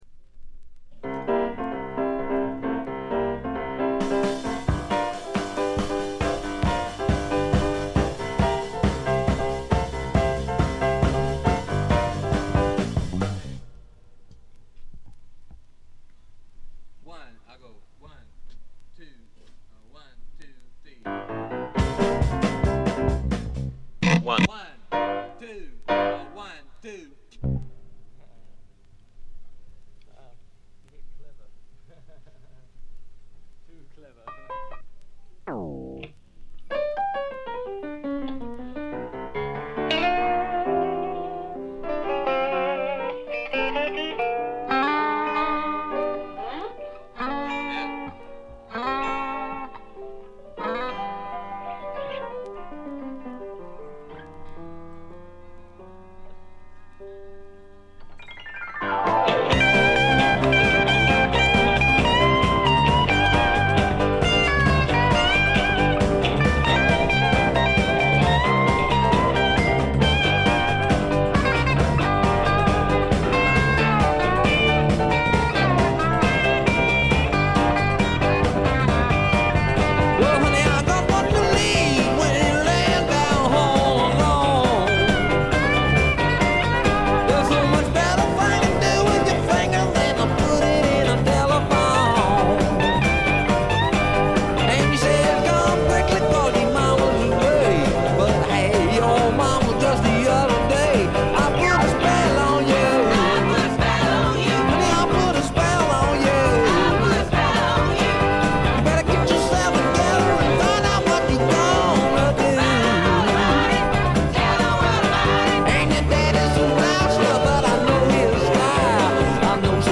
微細なチリプチ程度。
内容はいうまでもなくスワンプ風味の効いた素晴らしいシンガー・ソングライター・アルバム。
試聴曲は現品からの取り込み音源です。